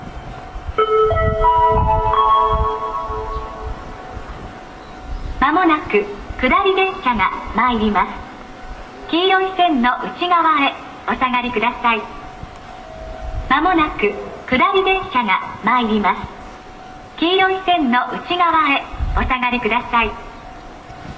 メロディーは一般的です。